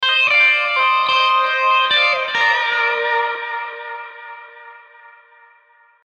Tag: 80 bpm Folk Loops Guitar Electric Loops 1.01 MB wav Key : Unknown